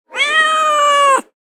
دانلود صدای گربه عصبانی از ساعد نیوز با لینک مستقیم و کیفیت بالا
جلوه های صوتی